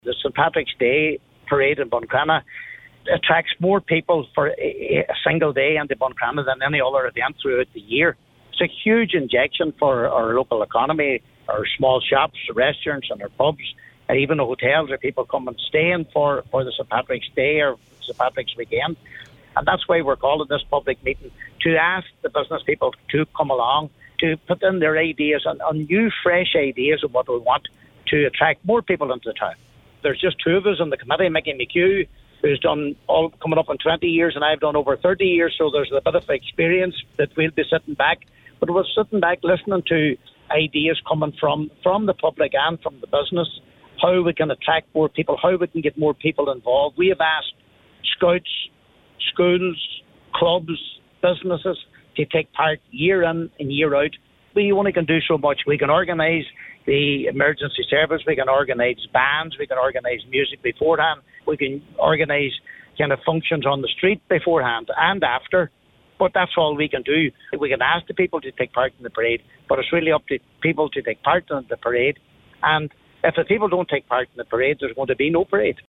Councillor Nicholas Crossan says support is vital for the success of the event: